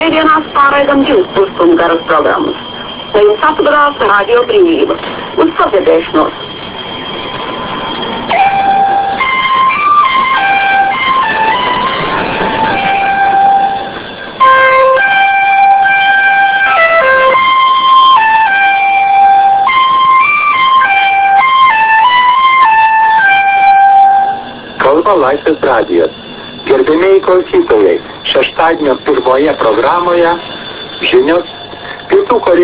Please , I send you the file real audio of non identificate radio from Russian.